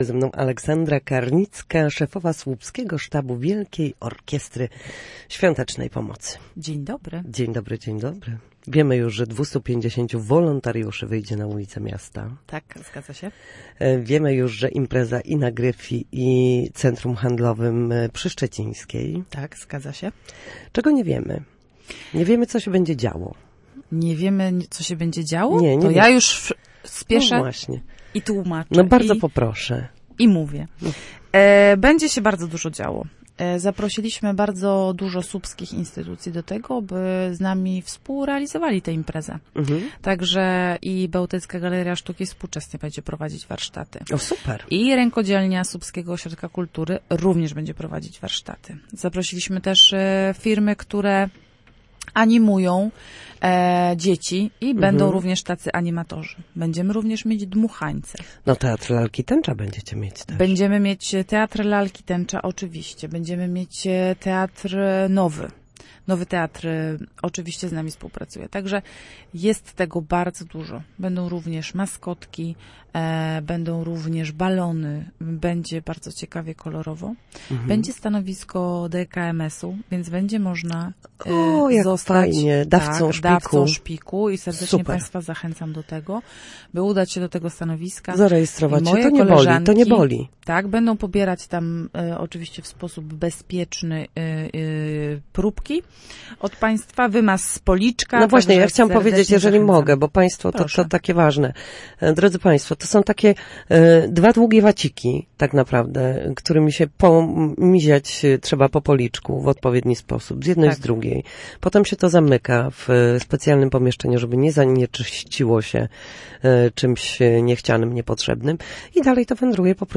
Na naszej antenie mówiła o tym, z czego będą mogli skorzystać słupszczanie podczas finału Orkiestry.